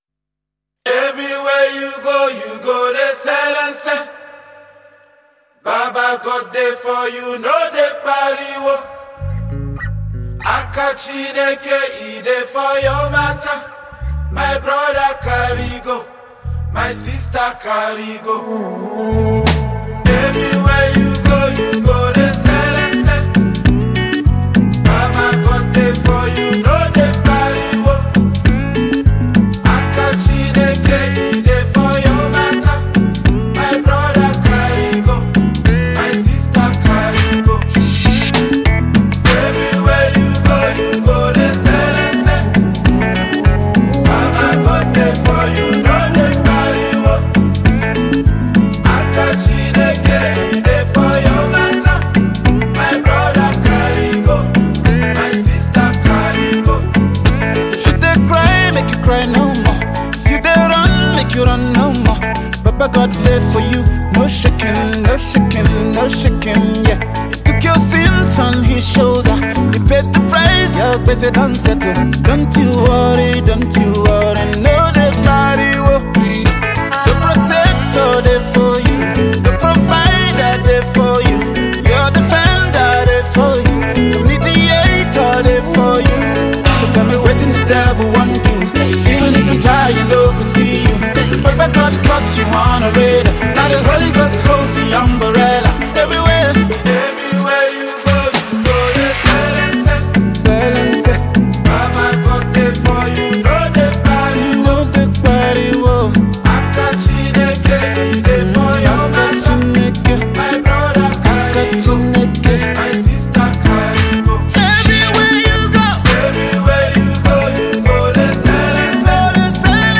GospelMusic
gospel afro song